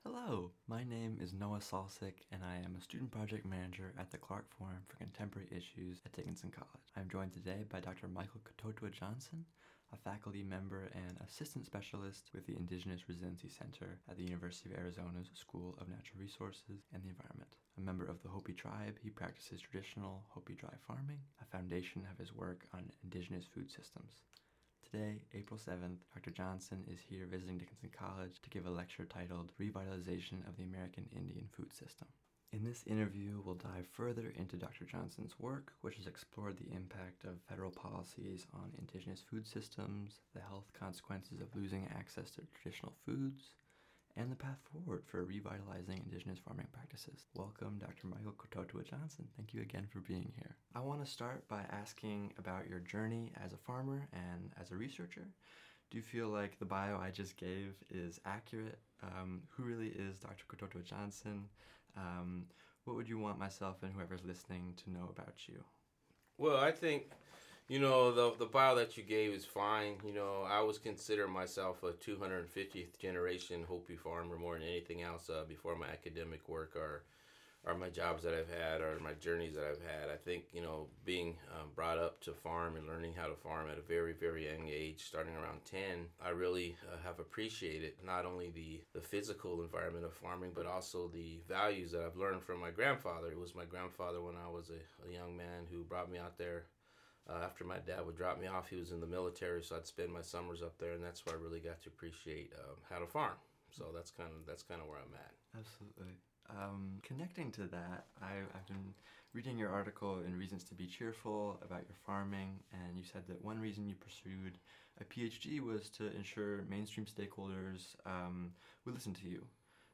Interview
Native-Ag-Interview-FINAL.mp3